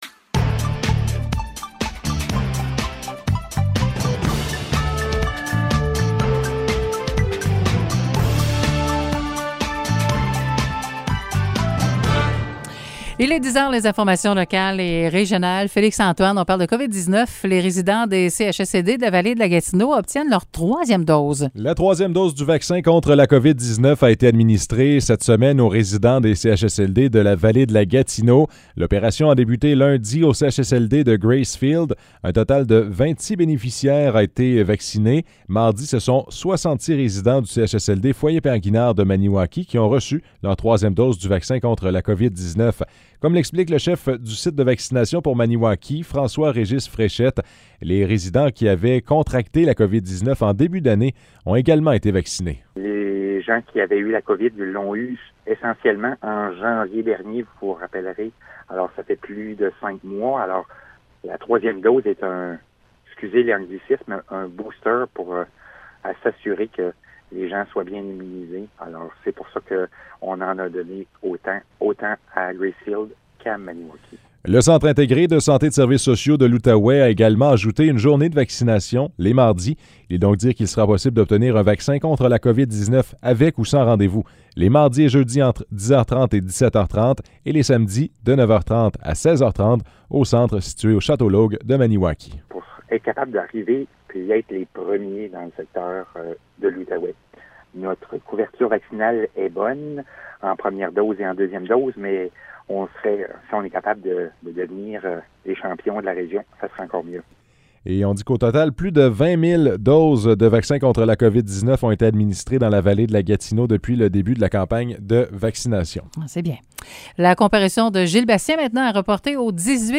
Nouvelles locales - 20 octobre 2021 - 10 h